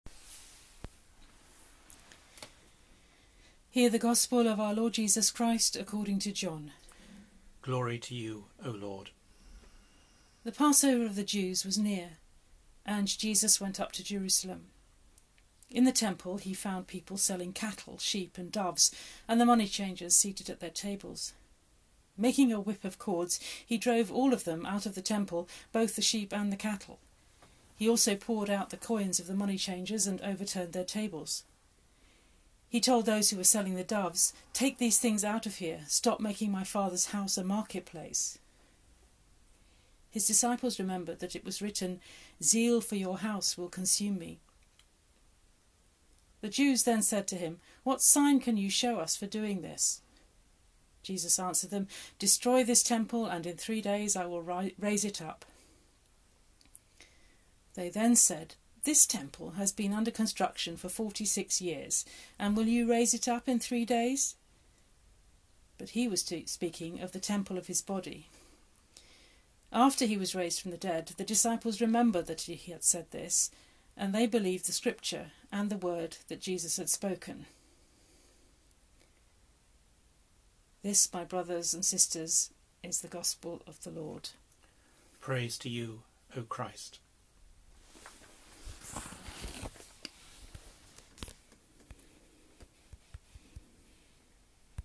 Gospel Reading
Gospel-reading-for-Lent-3B.mp3